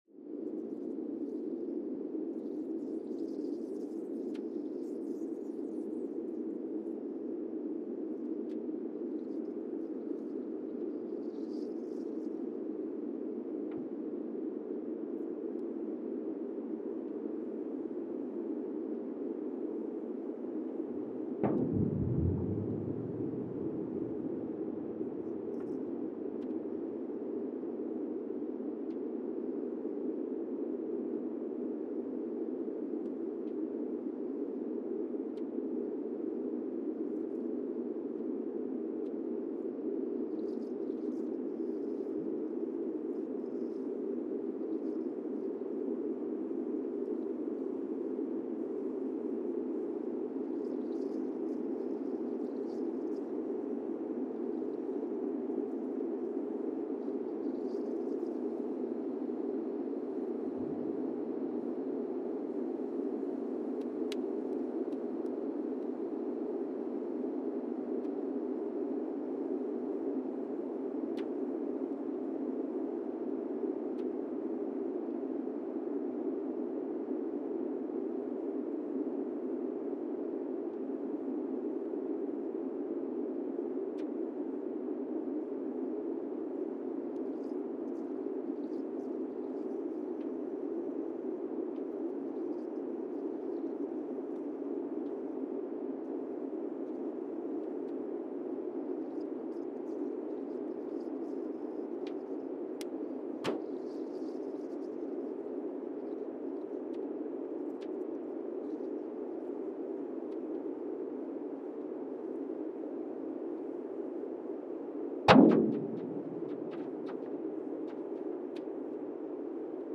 Monasavu, Fiji (seismic) archived on February 19, 2020
Station : MSVF (network: IRIS/IDA) at Monasavu, Fiji
Sensor : Teledyne Geotech KS-54000 borehole 3 component system
Speedup : ×1,800 (transposed up about 11 octaves)
Loop duration (audio) : 05:36 (stereo)
SoX post-processing : highpass -2 90 highpass -2 90